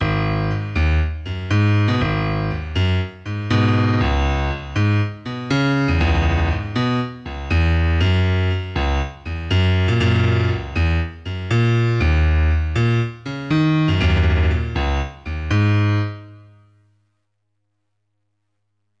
Original MIDI
Source melody - all samples generated from this